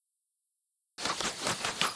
archer_volley_loop.wav